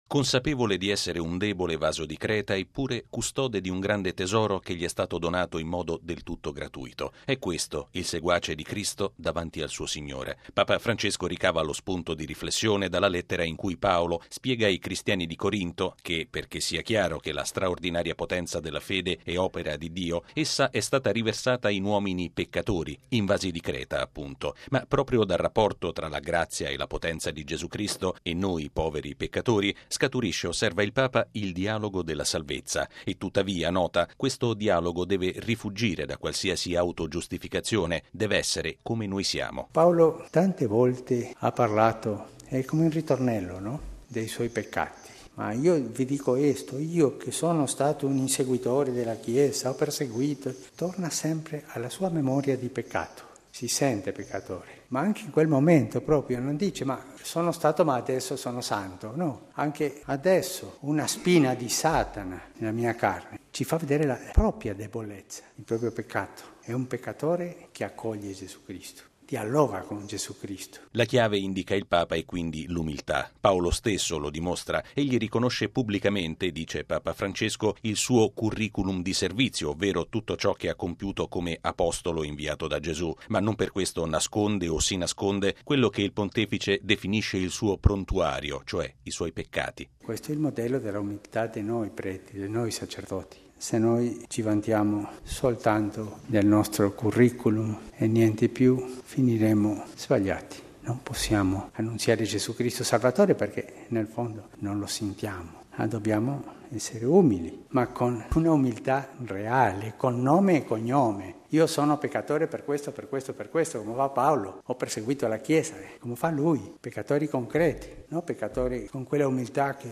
◊   L’unico modo per ricevere realmente il dono della salvezza di Cristo è riconoscerci con sincerità deboli e peccatori, evitando ogni forma di autogiustificazione. Papa Francesco lo ha affermato all’omelia della Messa di questa mattina, celebrata nella cappella di Casa S. Marta.